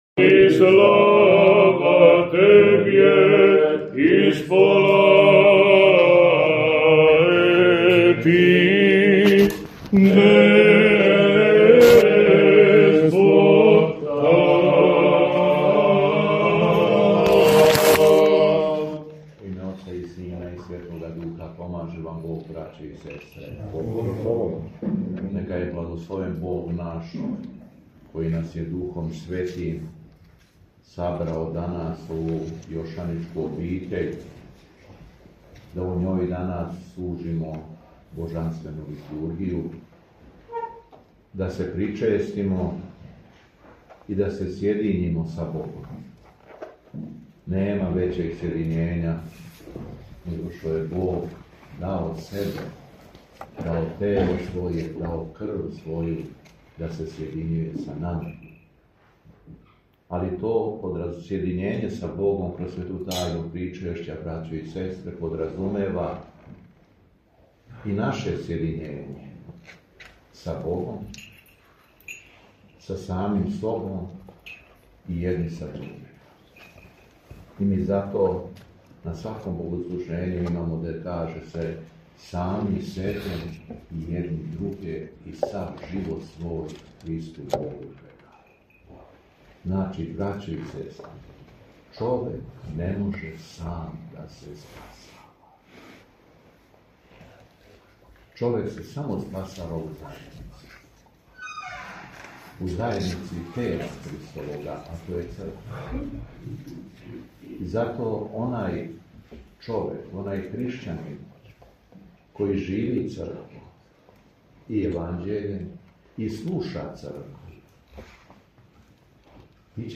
СВЕТА АРХИЈЕРЕЈСКА ЛИТУРГИЈА У ЈОШАНИЦИ
Беседа Његовог Високопреосвештенства Митрополита шумадијског г. Јована